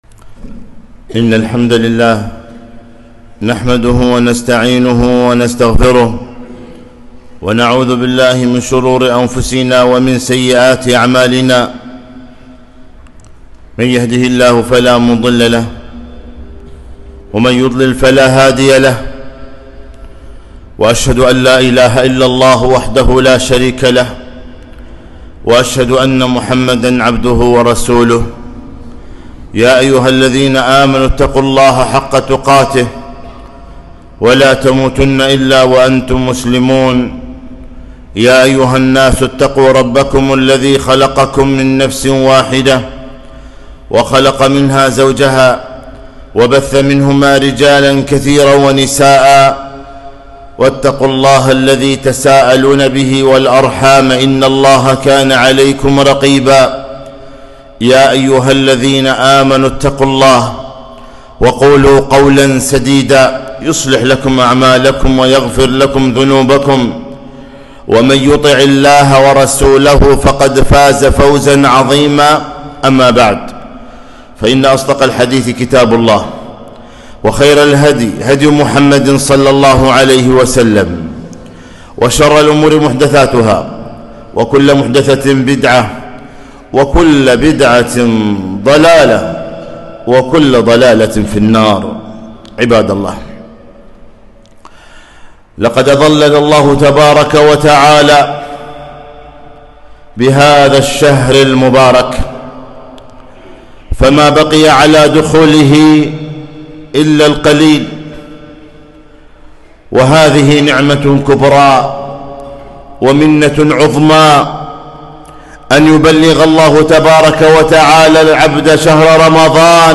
خطبة - أتاكم شهر رمضان